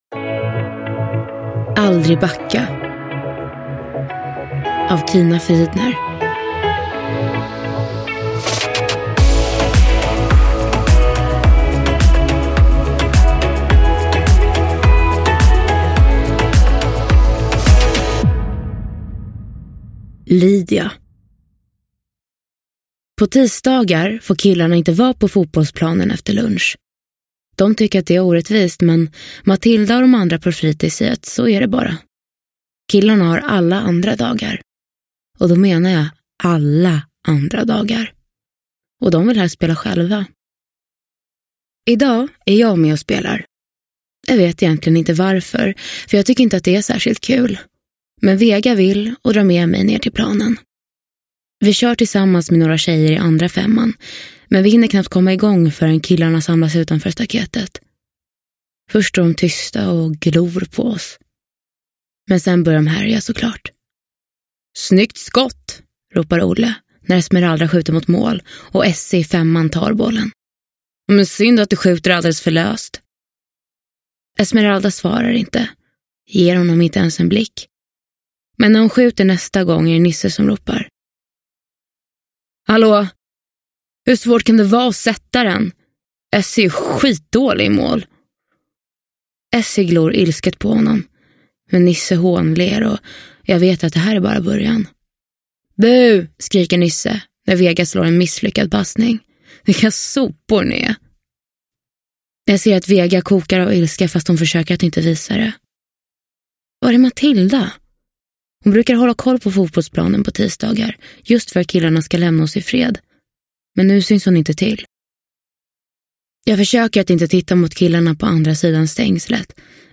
Aldrig backa – Ljudbok – Laddas ner